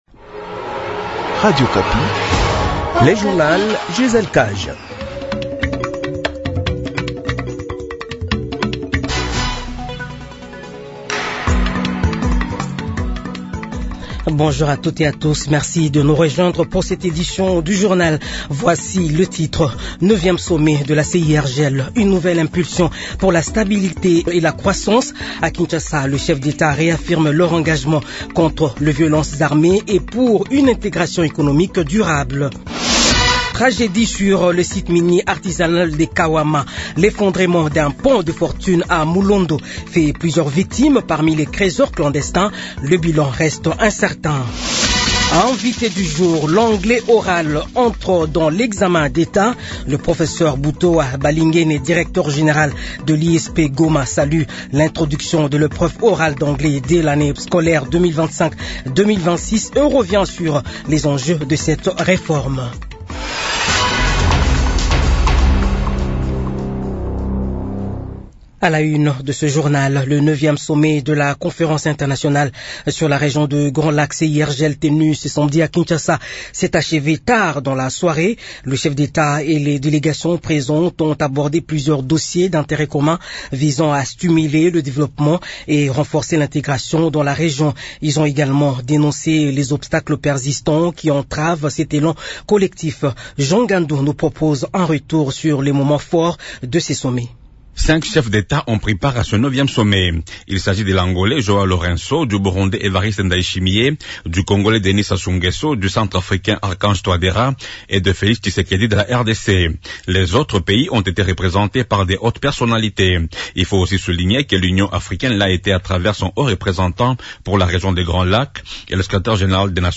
Edition de 15 heures de ce dimanche 16 novembre 2028